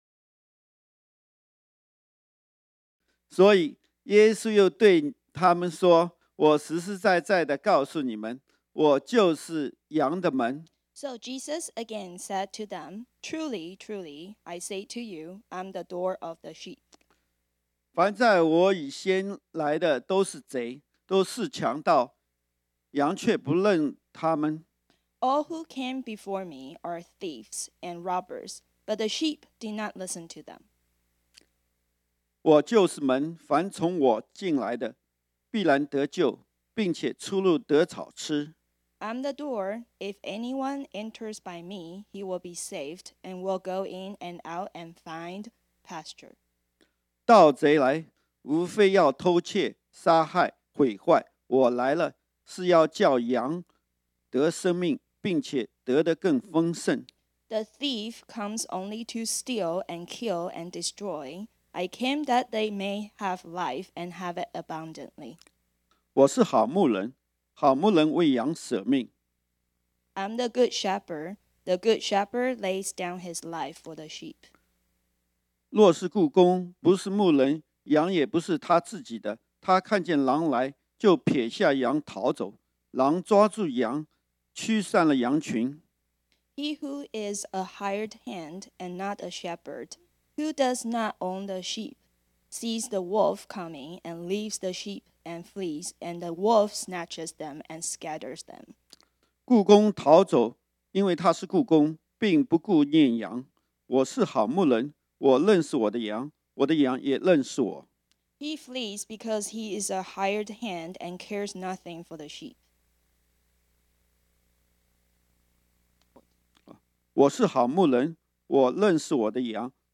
This sermon was originally preached on Sunday, February 2, 2020.